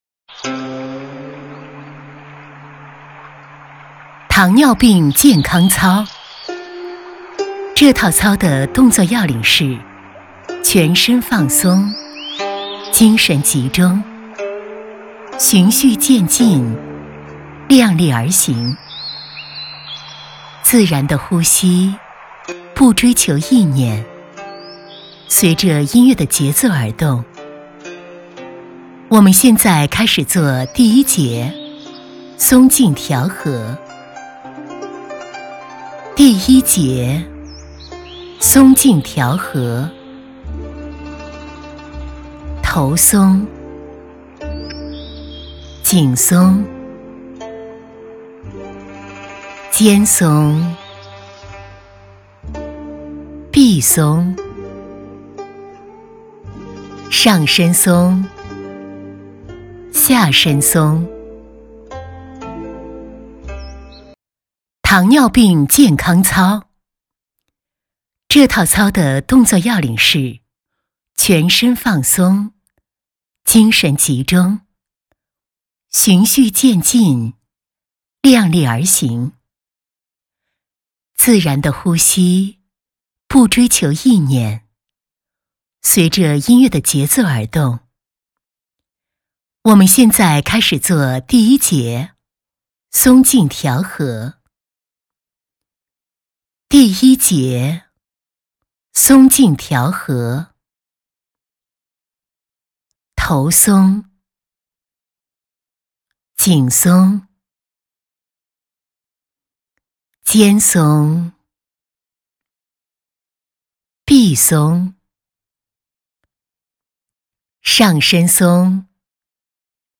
女80厚重专题 v80
女80--健康操-糖尿病.mp3